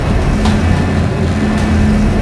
dockinginprogress.ogg